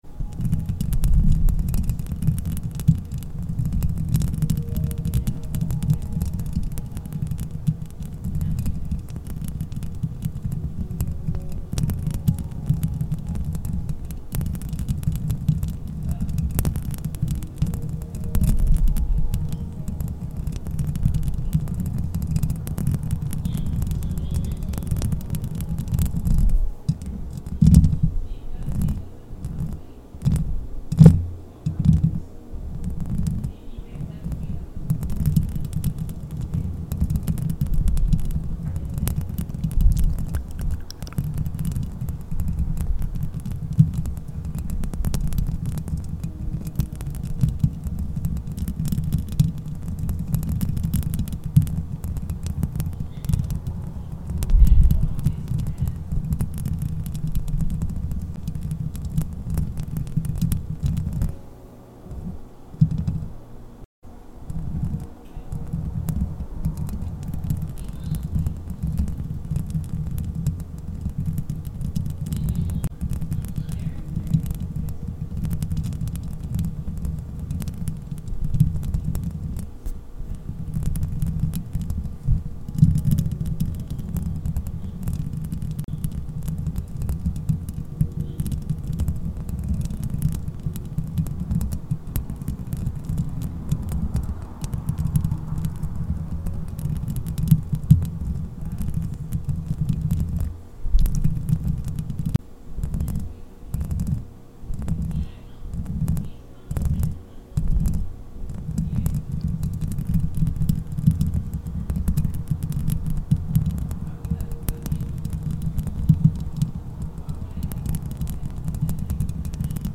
Pure Bare Mic Scratching No Sound Effects Free Download